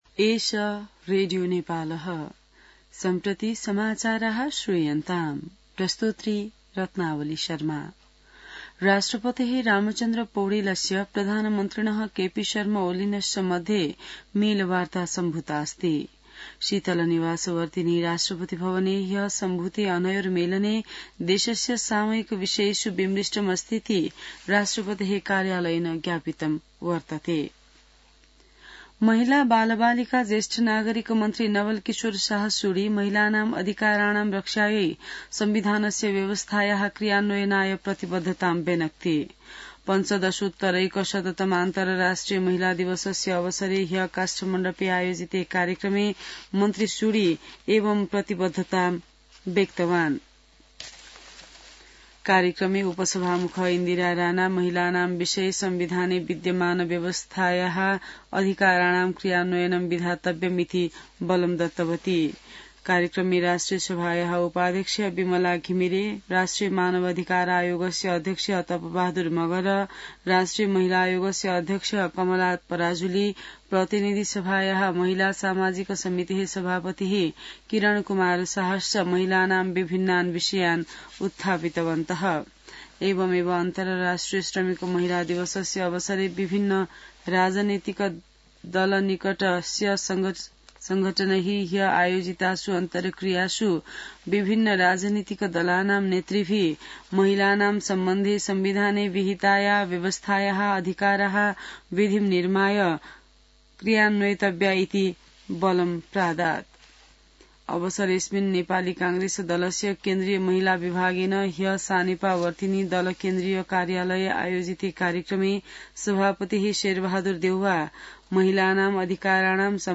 संस्कृत समाचार : २६ फागुन , २०८१